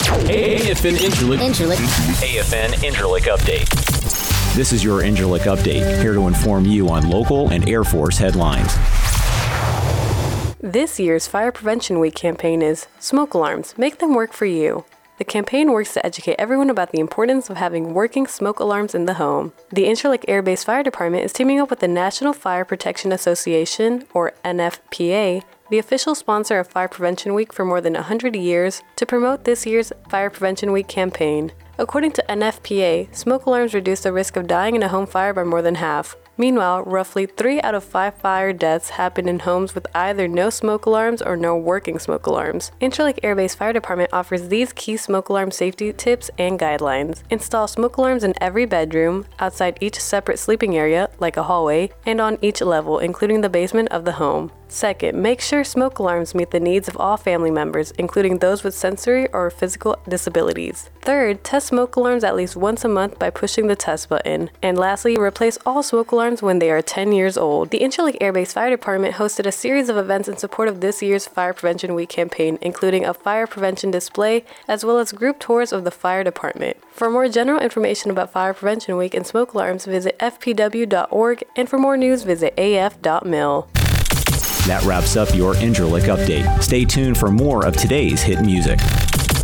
Defense Media Activity radio newscast